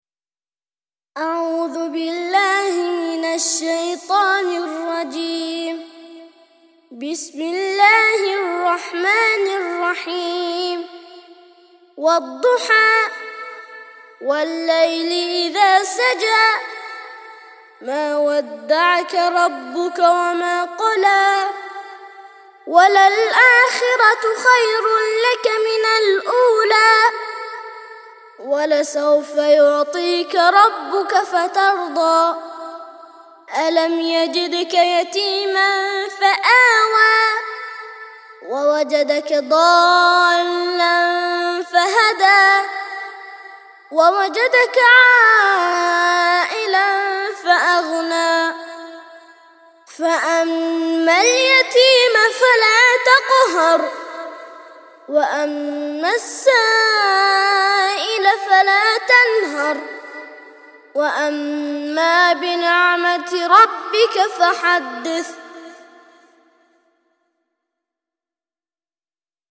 93- سورة الضحى - ترتيل سورة الضحى للأطفال لحفظ الملف في مجلد خاص اضغط بالزر الأيمن هنا ثم اختر (حفظ الهدف باسم - Save Target As) واختر المكان المناسب